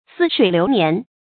成语繁体 佀水流年 成语简拼 ssln 常用程度 常用成语 感情色彩 中性成语 成语用法 偏正式；作主语、宾语；形容时间流逝之快 成语结构 偏正式成语 产生年代 古代成语 成语正音 似，不能读作“shì”。